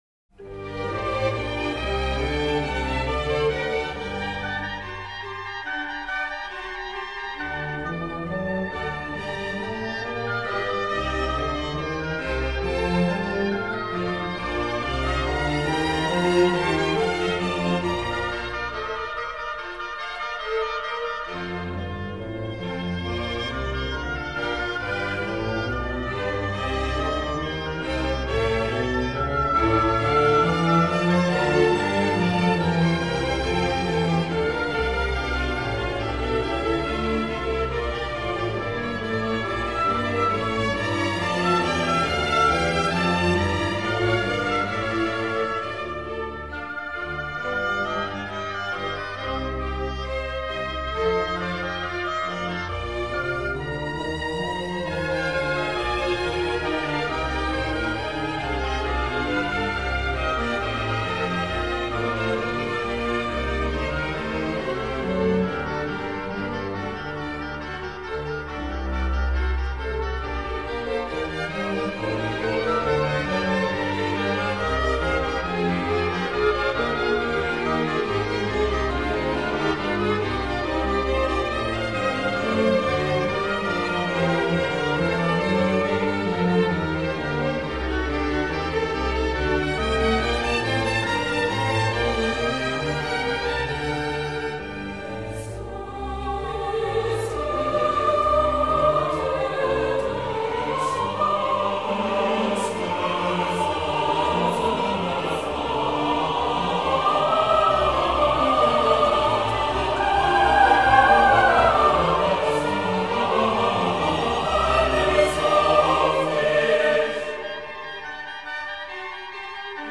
Chorus 1 / Chorale